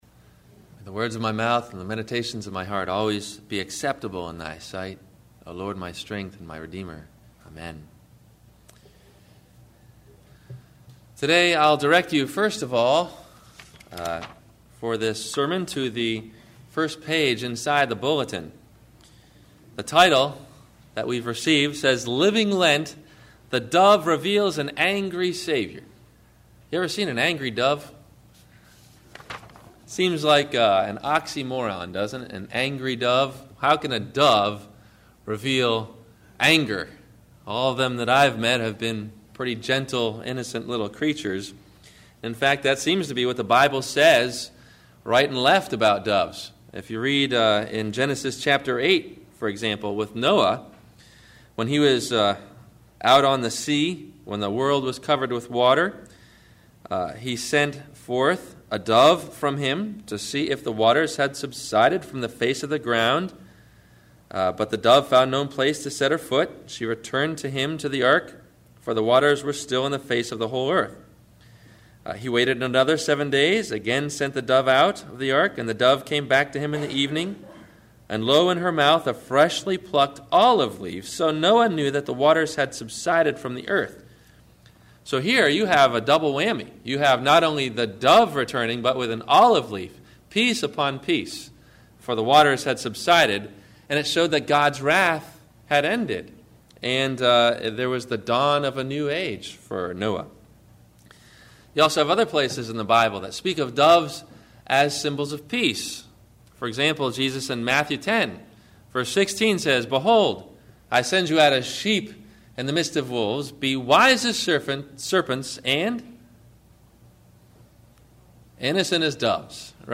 Wed. Lent – The Dove – Sermon – February 20 2008